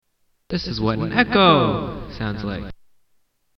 Echo sounds like
Tags: Science/Nature The Echo soundboard Echo Echolocation Sound Sound